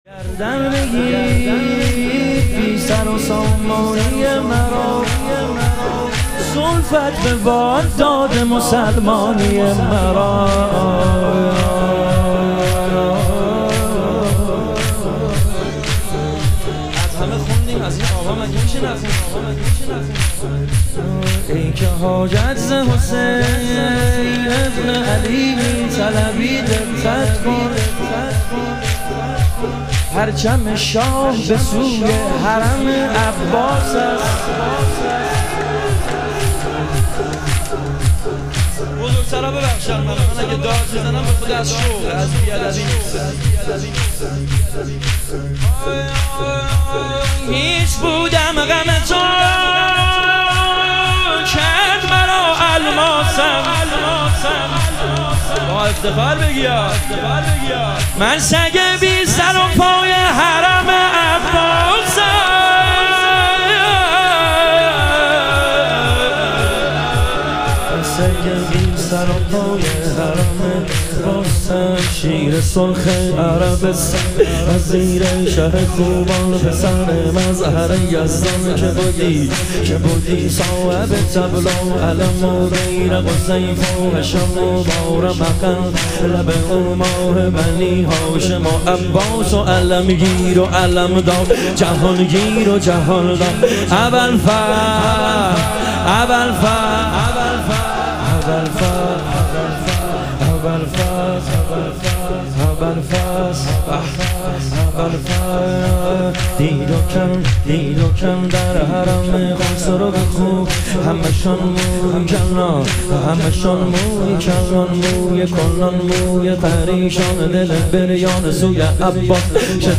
ایام فاطمیه اول - تک